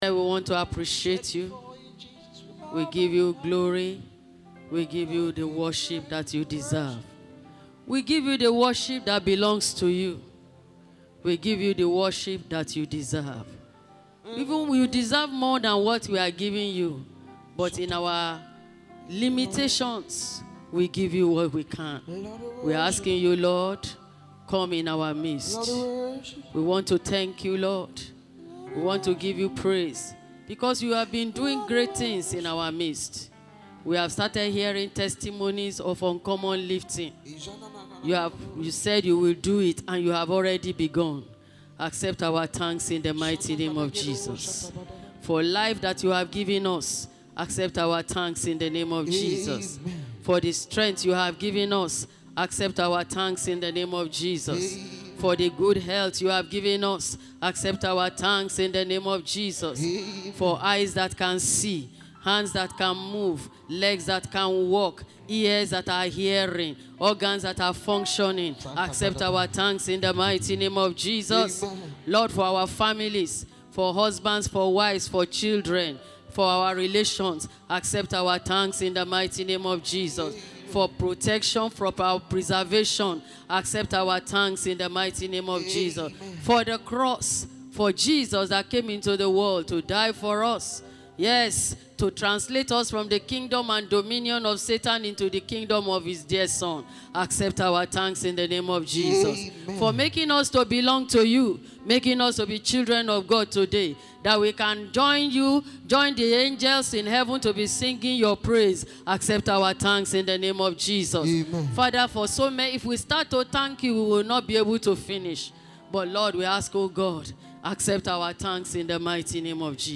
Post a comment Cancel reply Δ Sermon Categories Audio Sermon